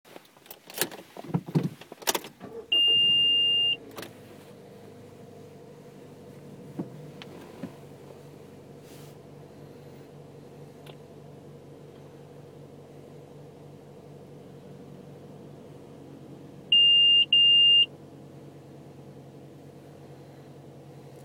Pries savaite atsirado keistas aukstas pypsejimas mazda 6 2005.
Uzvedant pasigirsta vienas aukstas pyptelejimas o po ~12 sekundziu dvigubas